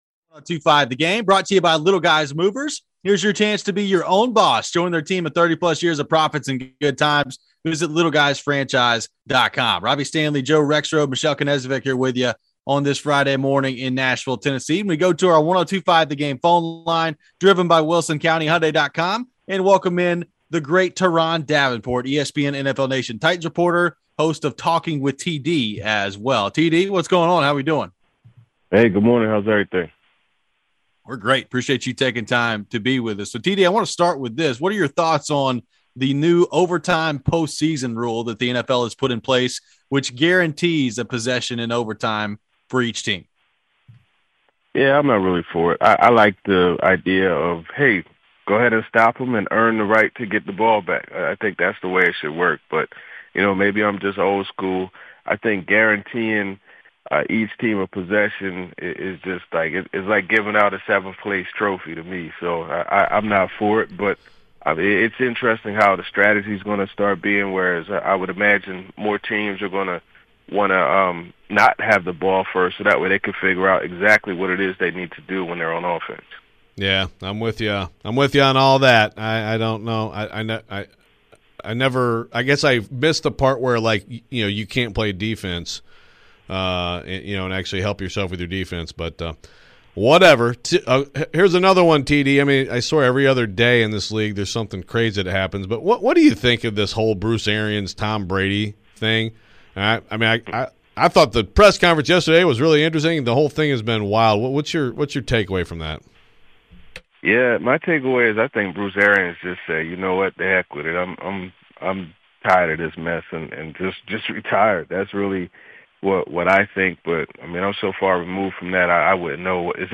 Will the Titans make any key trades in the upcoming future? Later in the hour, Tery Crisp Pred TV Analyst joins the show to talk ab...